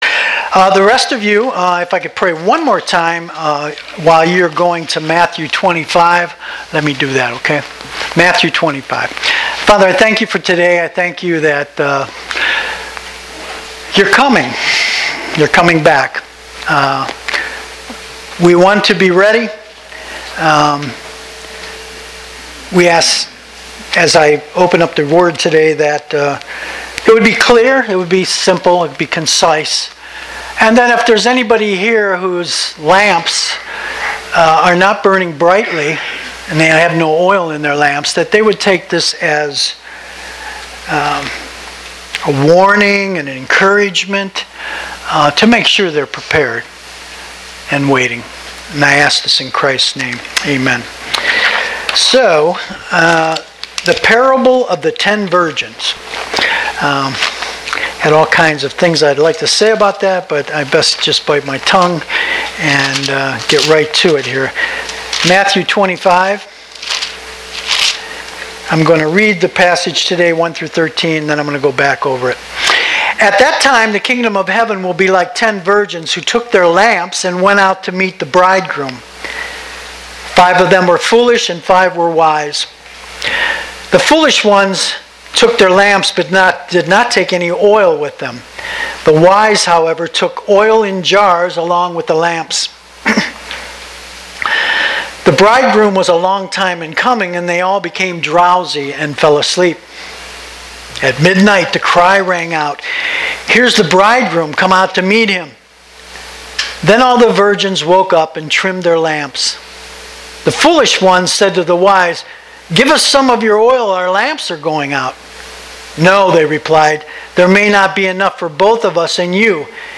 Grace Evangelical Bible Church » Sermons